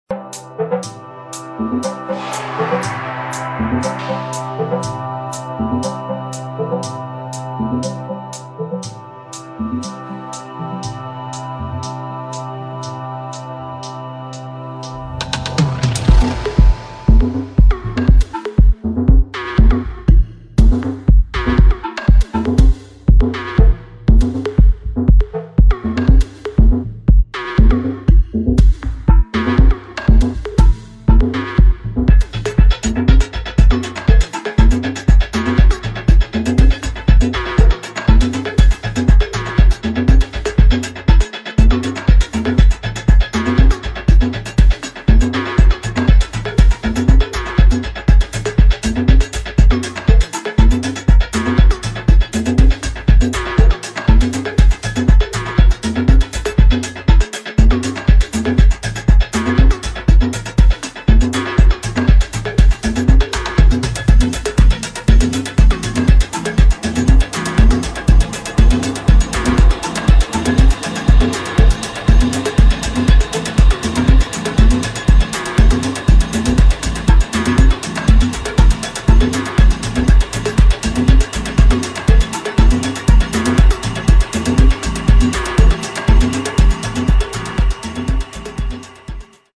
[ DEEP HOUSE / ACID / TECHNO ]